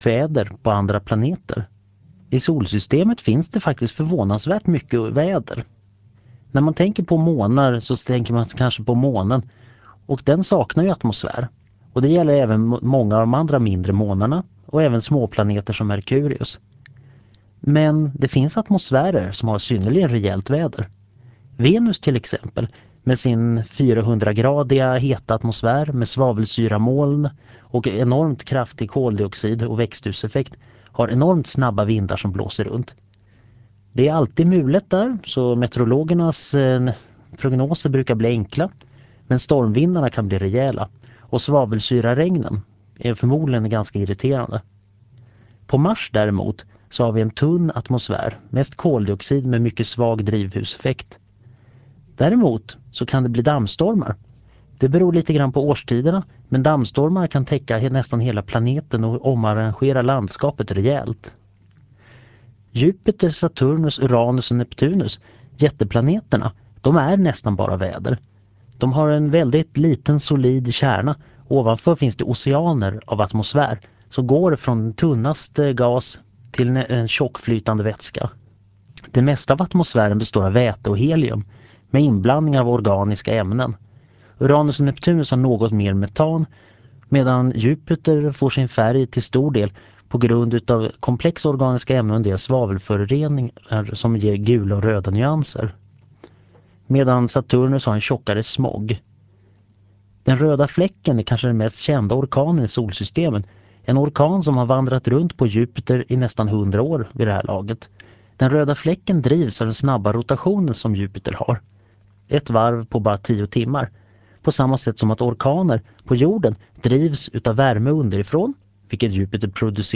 Föredraget handlar om astronomi och sänds den 03 oktober 1999 i Förklarade_Fenomen.